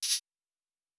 Sci-Fi Sounds / Interface / Error 10.wav
Error 10.wav